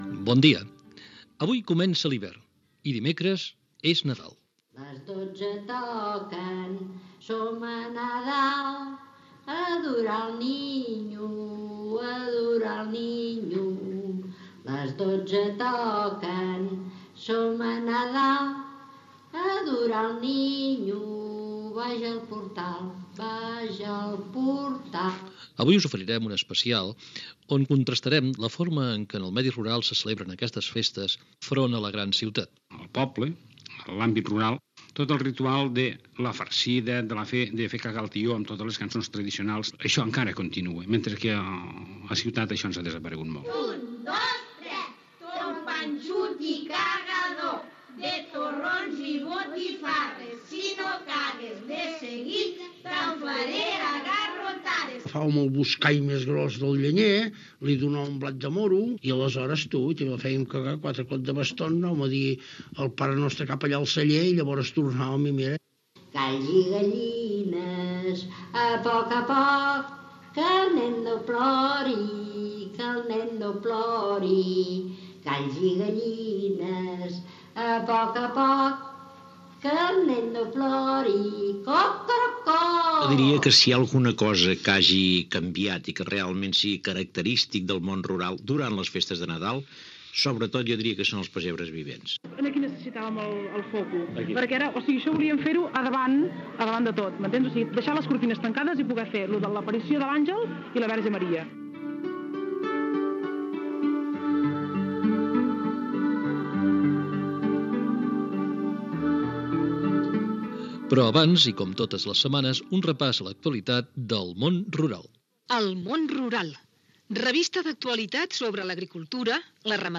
Programa dedicat a la celebració del Nadal al món rural, indicatiu
Divulgació